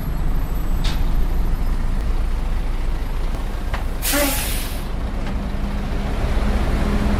bus_2_stopping.ogg